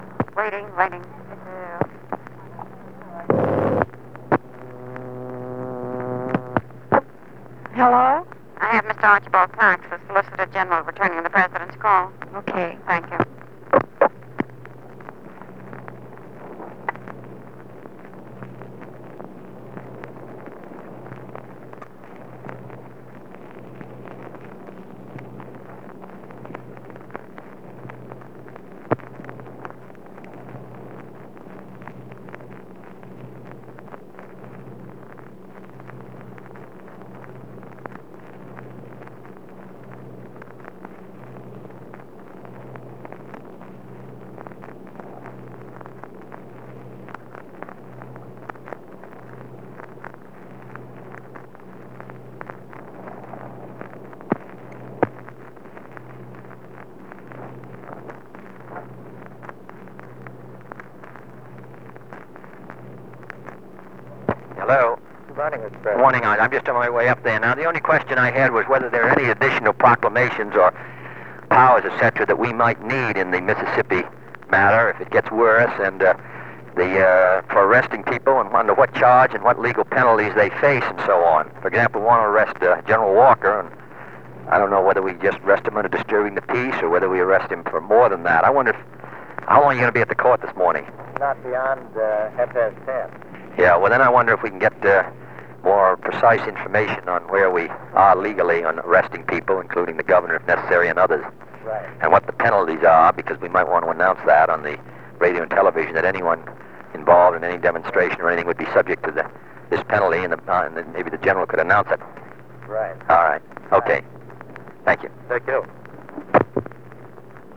Conversation with Archibald Cox
Secret White House Tapes | John F. Kennedy Presidency Conversation with Archibald Cox Rewind 10 seconds Play/Pause Fast-forward 10 seconds 0:00 Download audio Previous Meetings: Tape 121/A57.